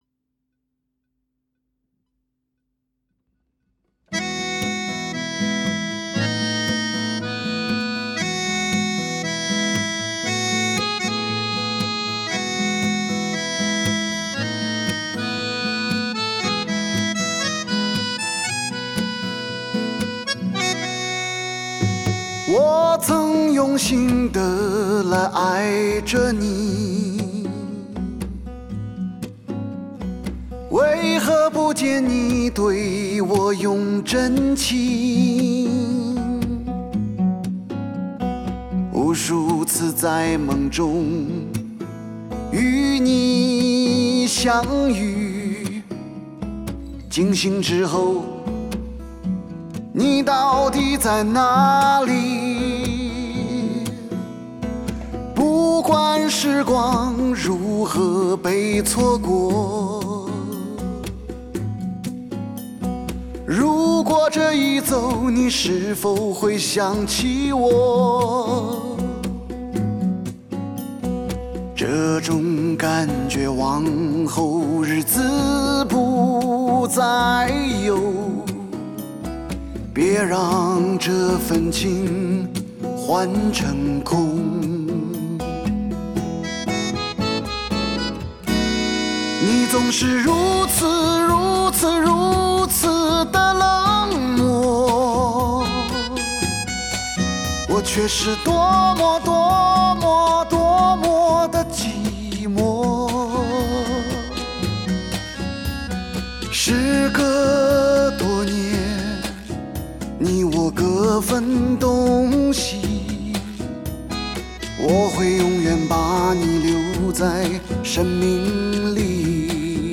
极为罕见的碰性噪音，魅力达至发烧的无上境界，吟唱出灵魂的味道。
细细地品味从不同角度传来的磁性柔情，和乐声与人声夹带着的男人的感伤，心灵在起落之间，得到了前所未有的恬静。